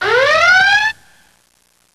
rabhadh.wav